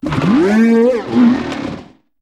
Cri de Motorizard dans Pokémon HOME.